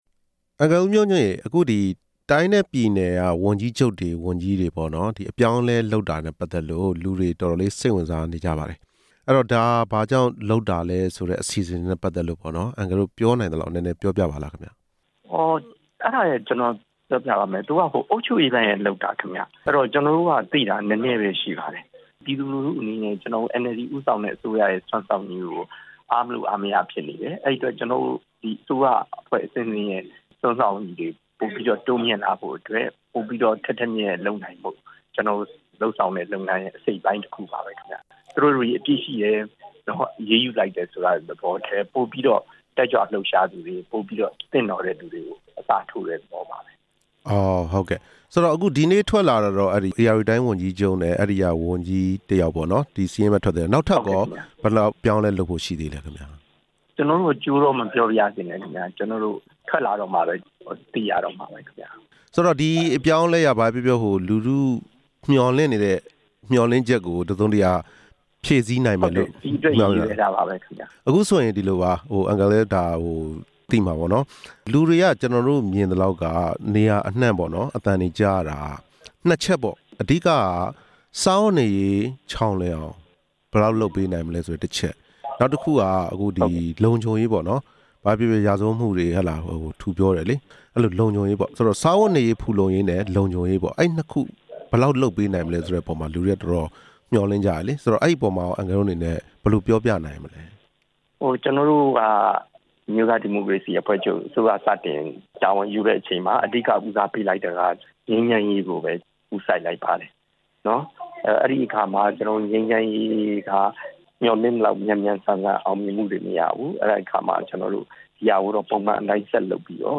ဝန်ကြီးချုပ်နဲ့ ဝန်ကြီးအချို့ အပြောင်းအလဲ အန်အယ်လ်ဒီပါတီနဲ့ မေးမြန်းချက်